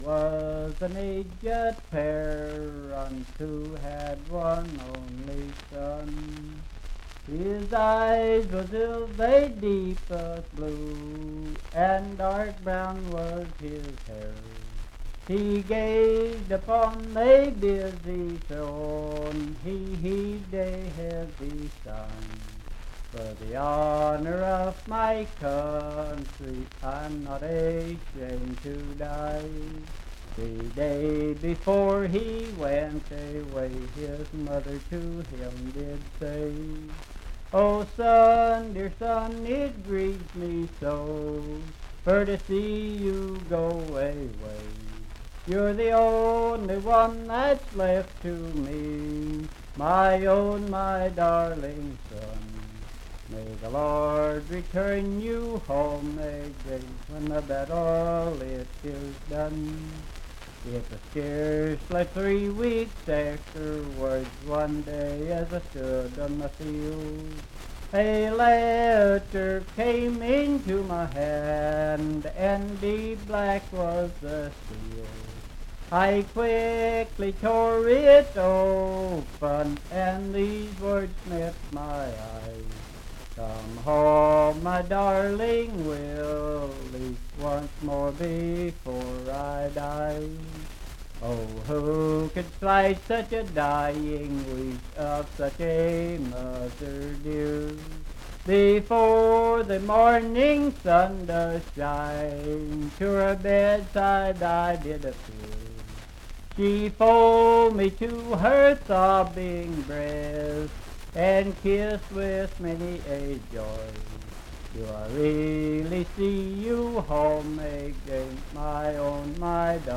Unaccompanied vocal music
Verse-refrain 7d(4).
Voice (sung)